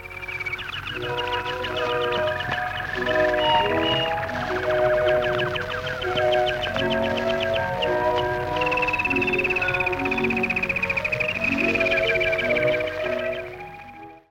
Música de l'espai de necrològiques